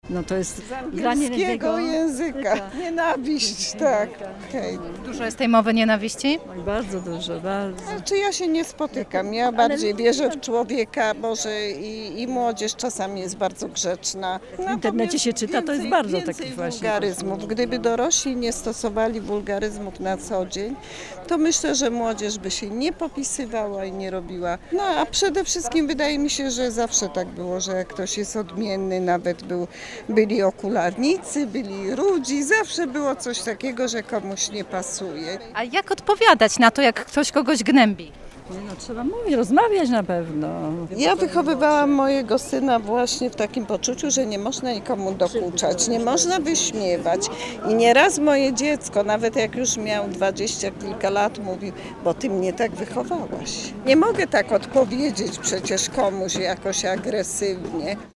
Spytaliśmy wrocławian, czy wiedzą czym jest hejt i jak na niego reagować.